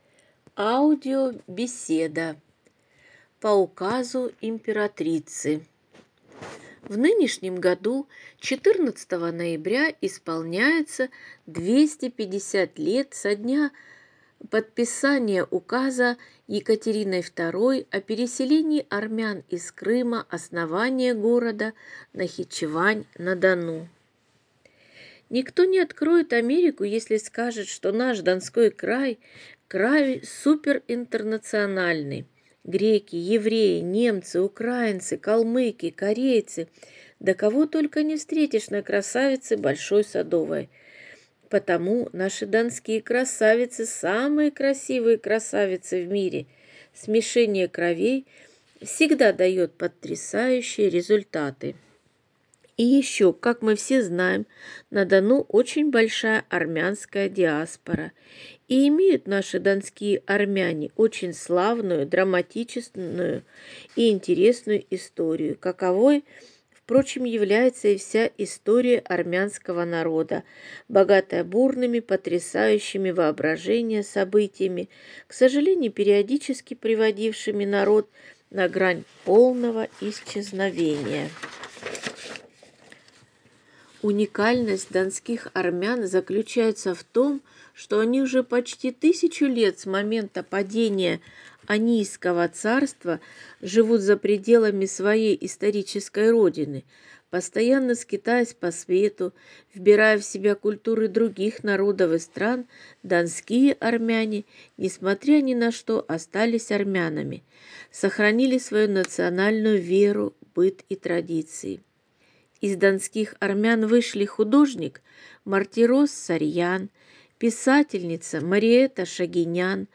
По указу Императрицы!» напомнит об известных актерах, художниках и одной писательнице – выходцах из донских армян. В завершении беседы прозвучат поэтические строки А.С. Пушкина.